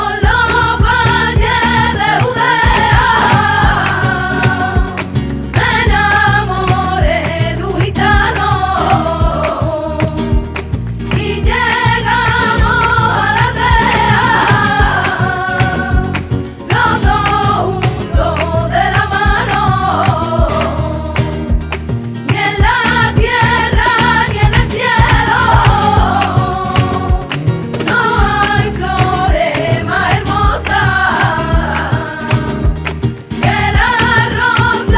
# Flamenco